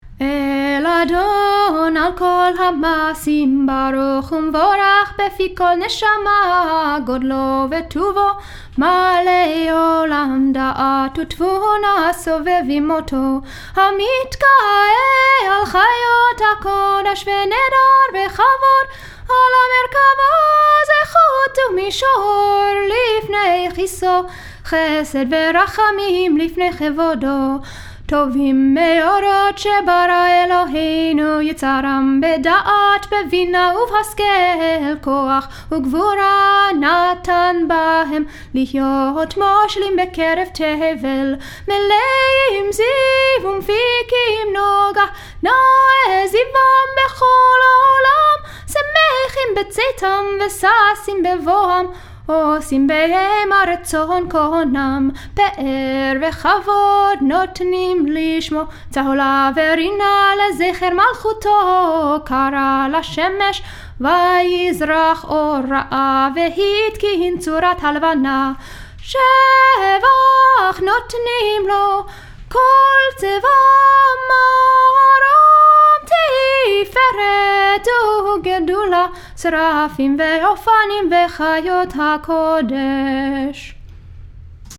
Eil Adon, traditional melody, text from Shabbat morning liturgy (recorded 2016).